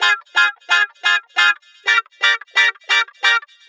Electric Guitar 01.wav